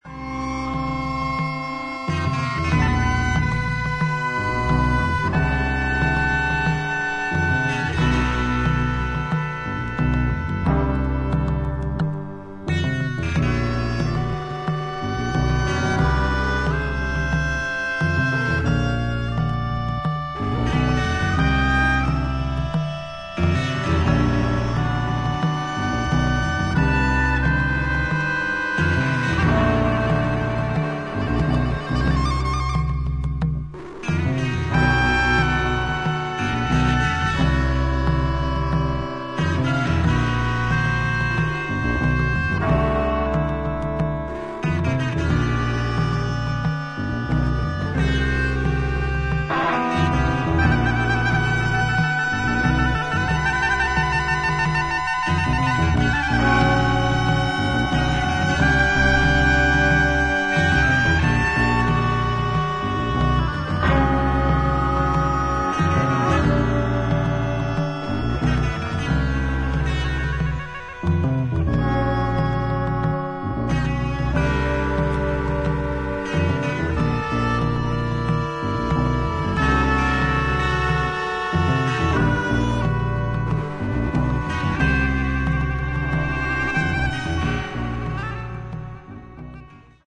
インドのラーガとヨーロッパ伝統音楽にロックの前衛性を織り交ぜ